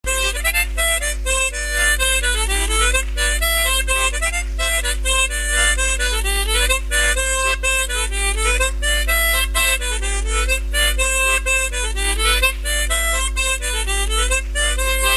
Crossac
Résumé instrumental
danse : branle : courante, maraîchine
Pièce musicale inédite